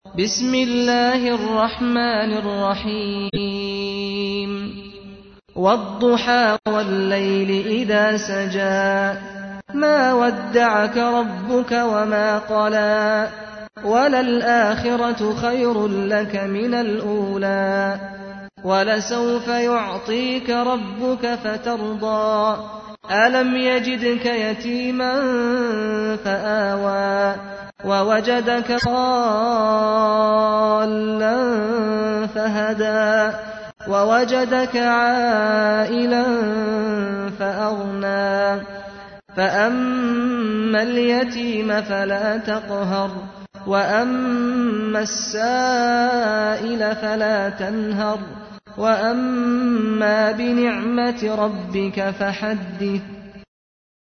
تحميل : 93. سورة الضحى / القارئ سعد الغامدي / القرآن الكريم / موقع يا حسين